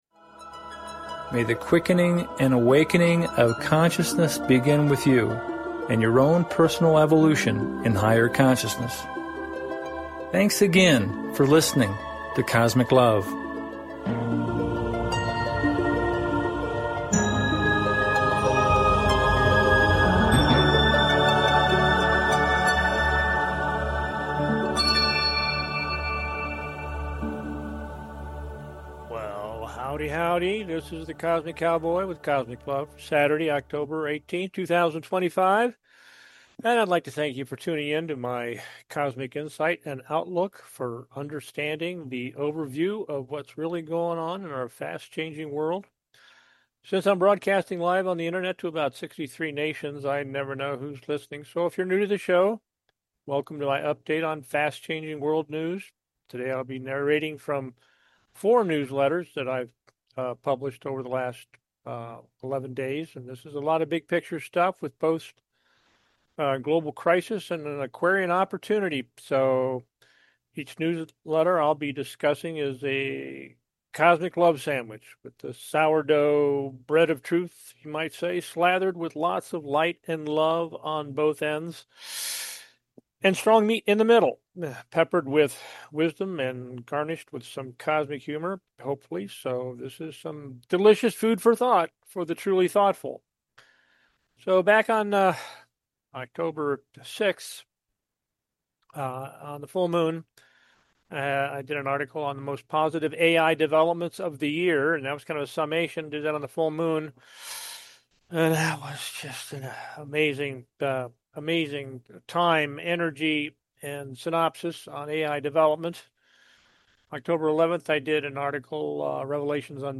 Cosmic LOVE Talk Show